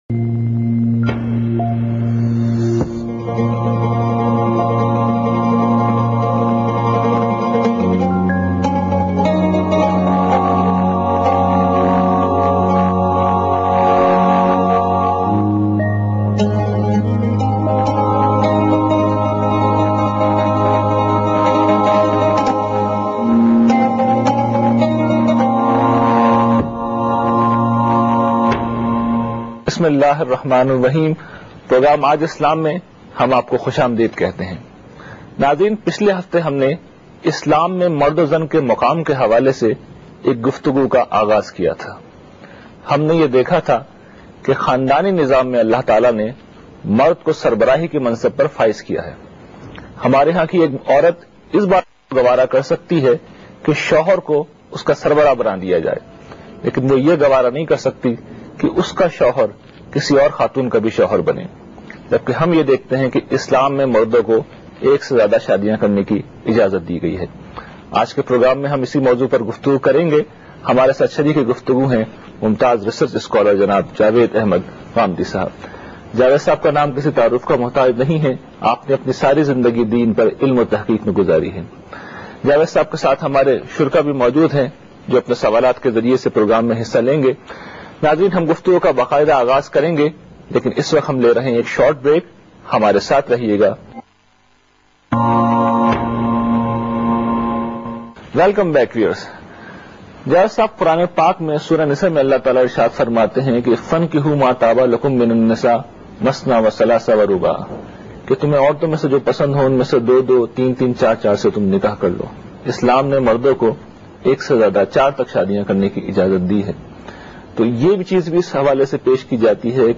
Category: TV Programs / Aaj Tv / Aaj Islam /
Ghamidi speaks about polygamy in Islam in program Aaj Islam on Aaj Tv.